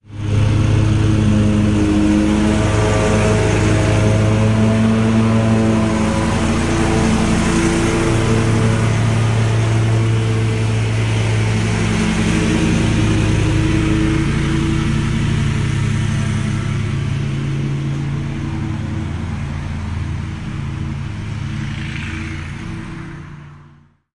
随机比特的声音 1 " 剪草机
描述：草用割草机切割，用H2 Zoom Recorder 44.1 wav记录。
Tag: 切割机 WAV 马达 切片 园林绿化 切割 草坪